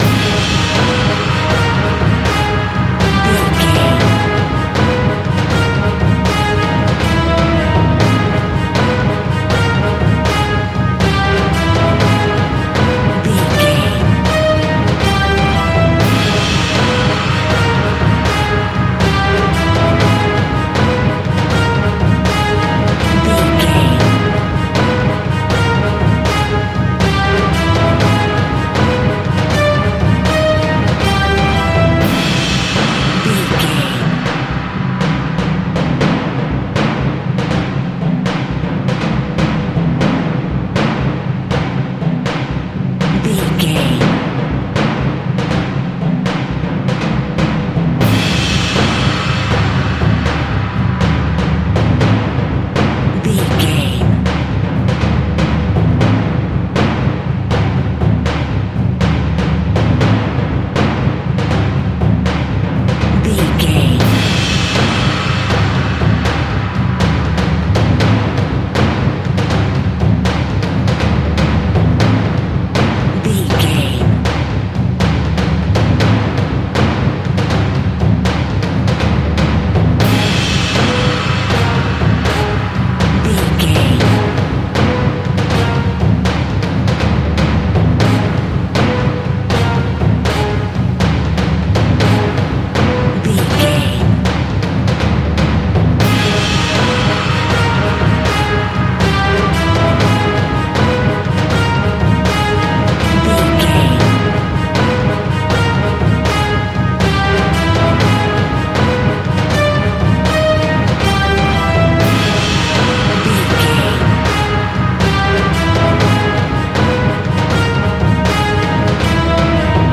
Thriller
Aeolian/Minor
Fast
tension
ominous
suspense
eerie
brass
strings
drums
percussion
viola
orchestral instruments